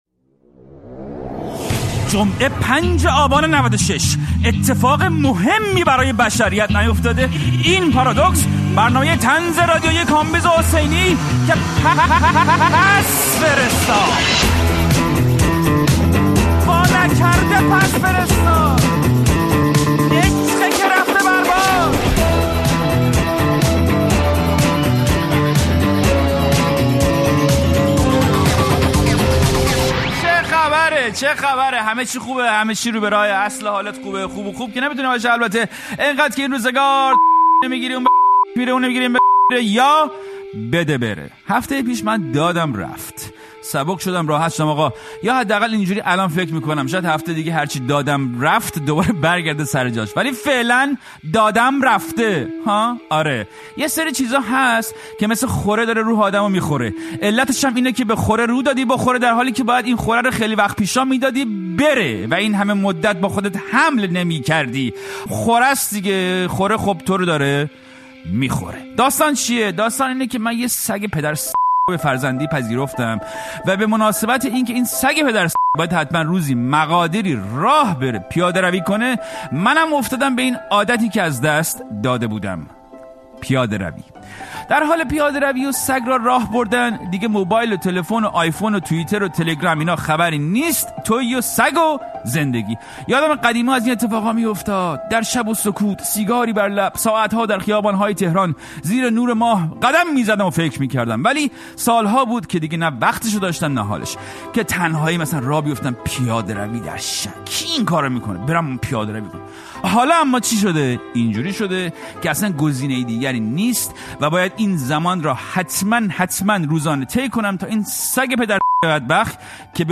پارادوکس با کامبیز حسینی؛ گفت‌وگو